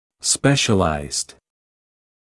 [‘speʃəlaɪzd][‘спэшэлайзд]специализированный (British English specialised); 2-я и 3-я форма от to specialize